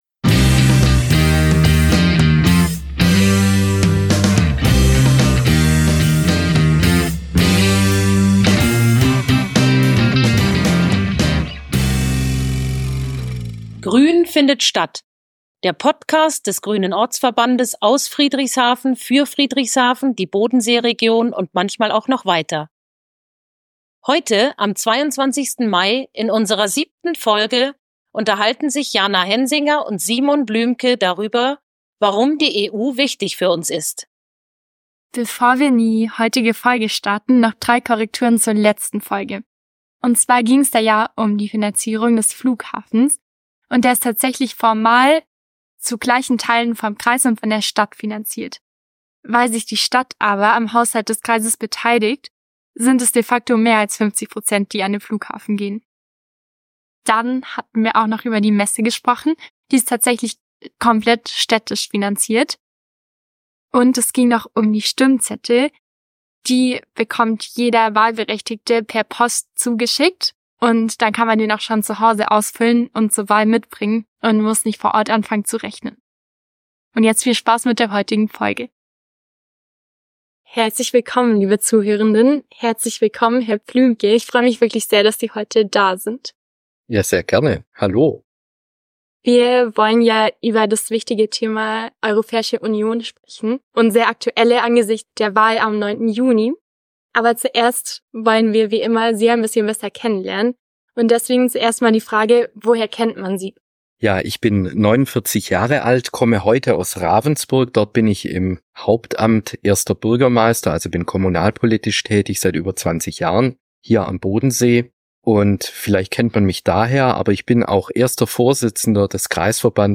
Zu diesen spannenden Fragen teilen die beiden ihre Gedanken und ihr Wissen.